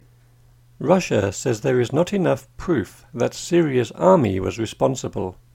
DICTATION 8